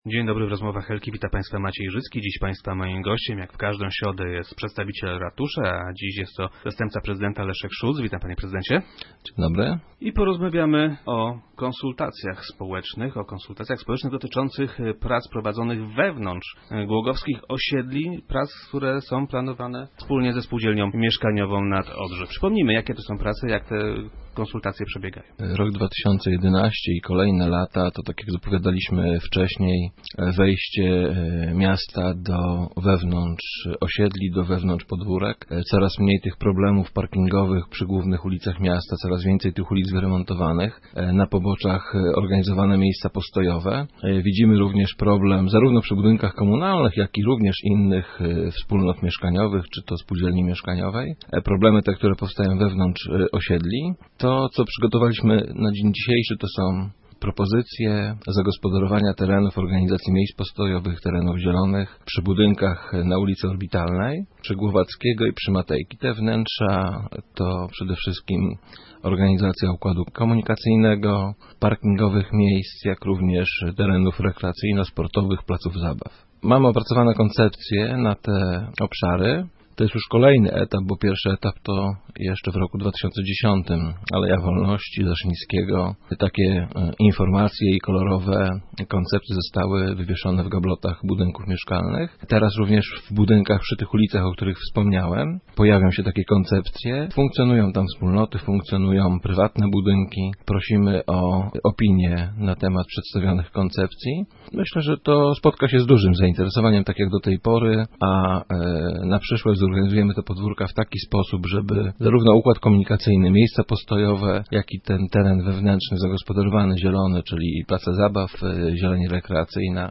- Mamy już opracowane odpowiednie koncepcje. Wkrótce wywieszone będą one w gablotach w pobliskich blokach. Prosimy lokatorów o opinie na temat tych koncepcji - powiedział wiceprezydent Szulc, który był dziś gościem Rozmów Elki.